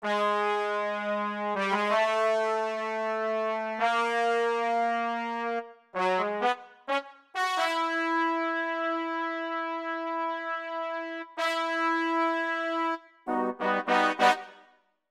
14 brass 4 C1.wav